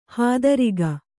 ♪ hādariga